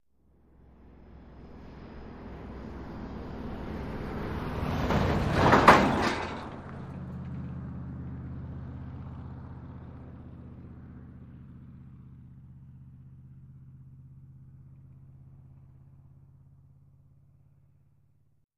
Car Tires Over Railroad Tracks 4x